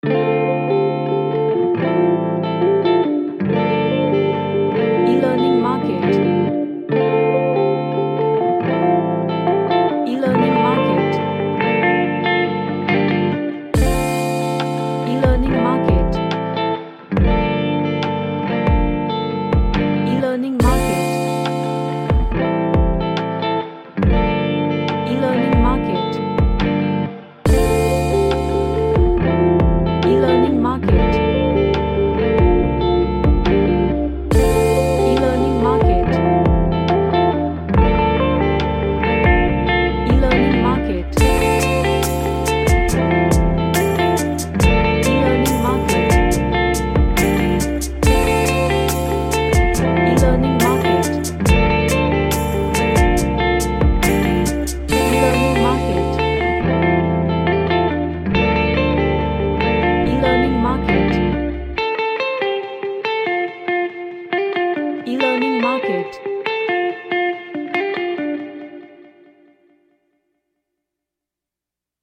A Chill track with percussions